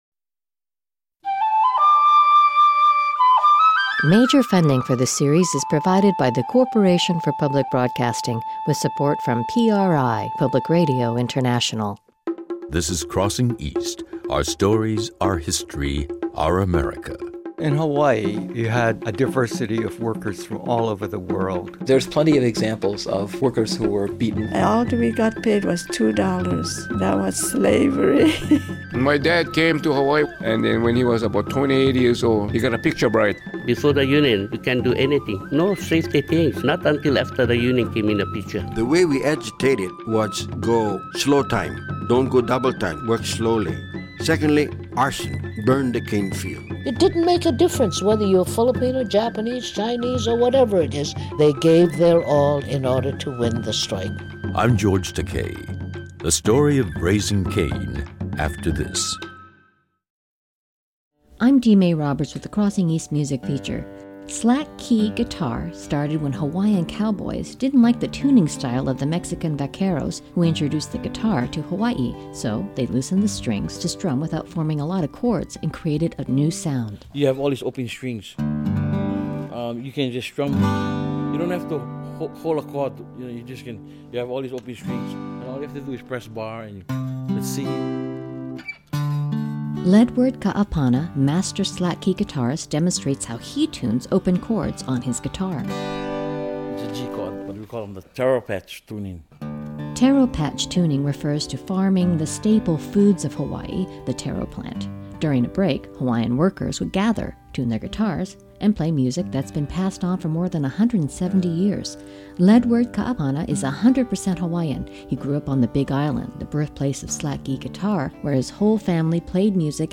Hole Hole Bushi songs